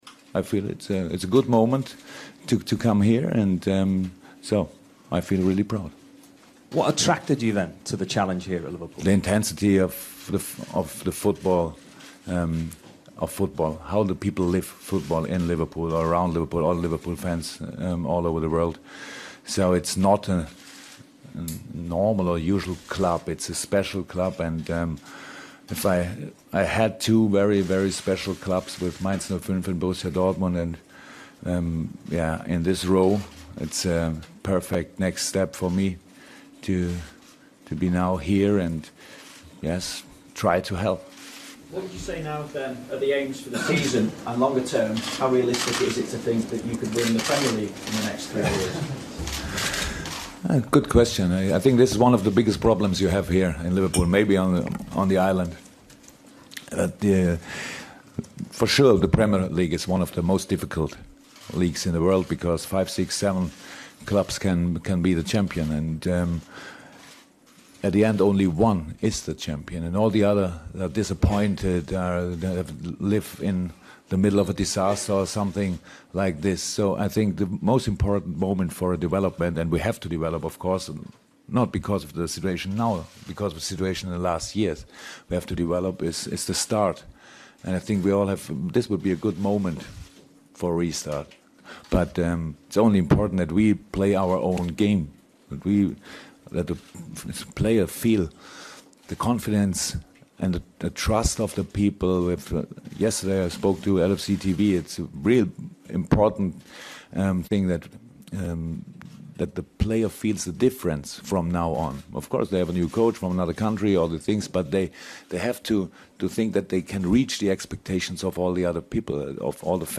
Listen to Jurgen Klopp's first press conference as Liverpool manager.